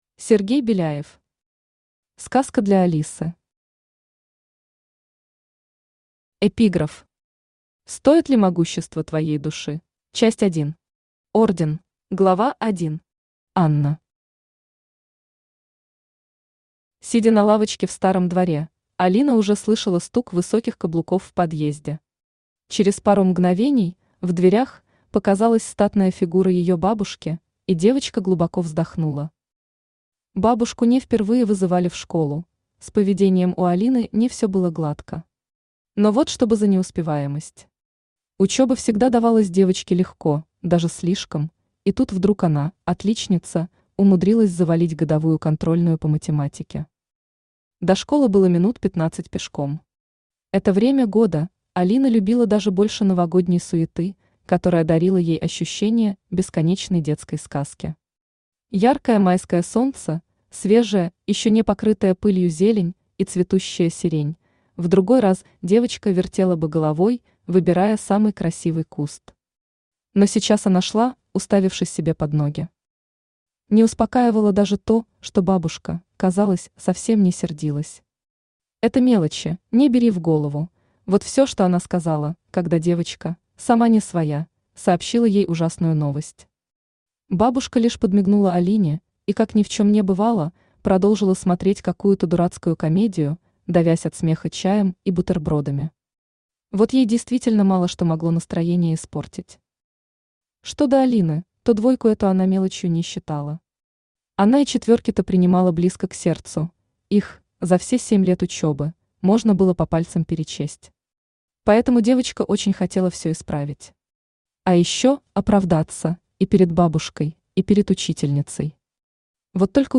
Аудиокнига Сказка для Алисы | Библиотека аудиокниг
Aудиокнига Сказка для Алисы Автор Сергей Беляев Читает аудиокнигу Авточтец ЛитРес.